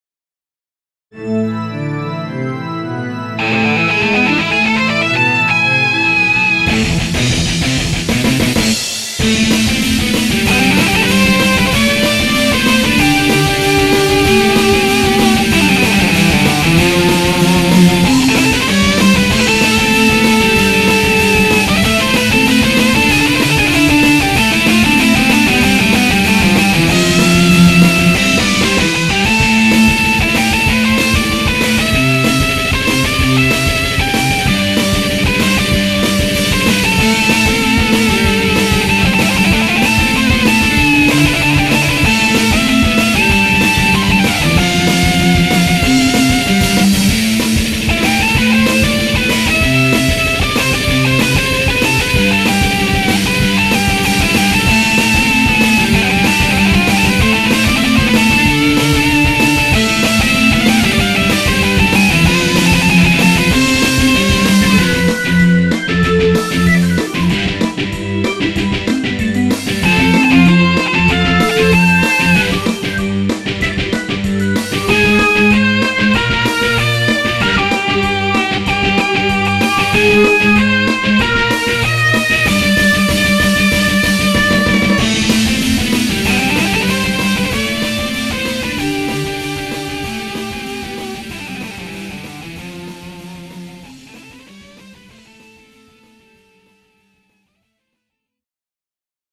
RealStratの練習用。